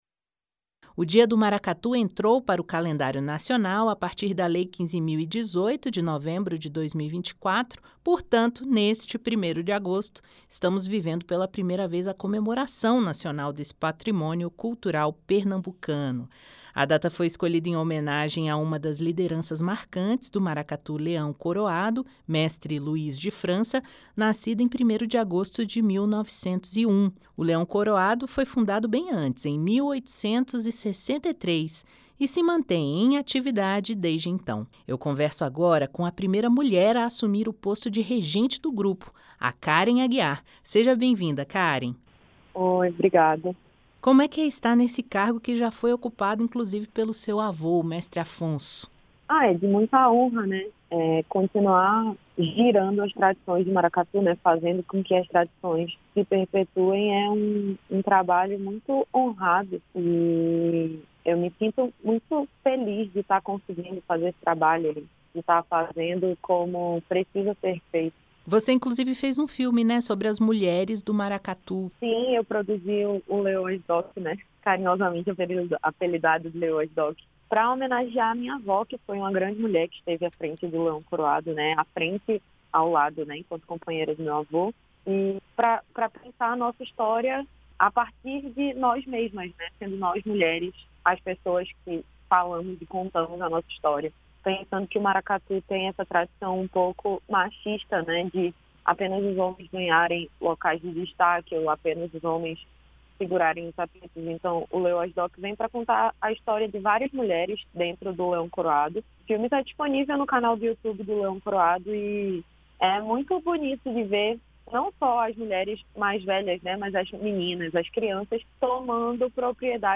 Dia do Maracatu - Entrevista